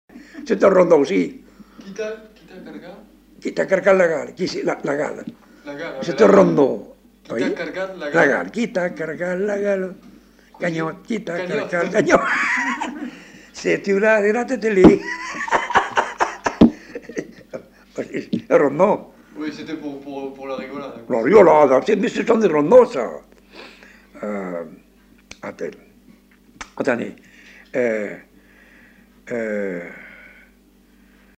Aire culturelle : Bazadais
Genre : chant
Effectif : 1
Type de voix : voix d'homme
Production du son : chanté
Danse : rondeau